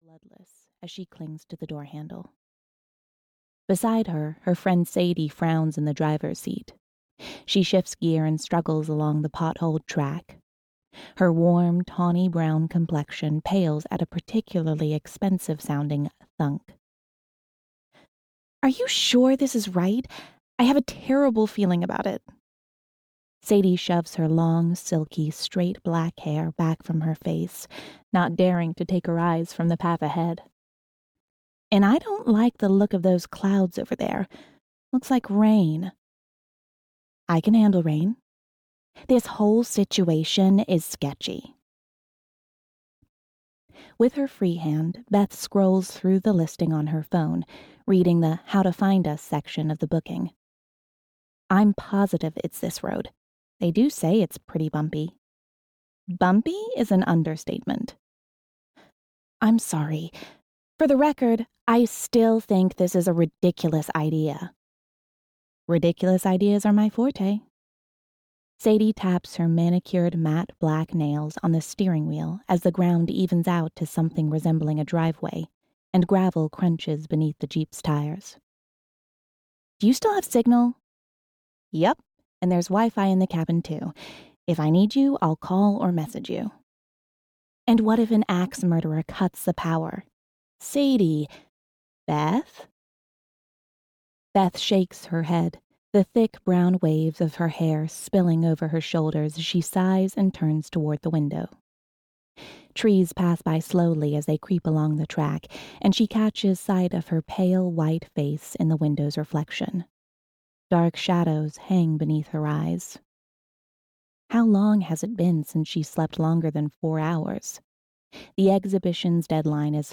Rhythm (EN) audiokniha
Ukázka z knihy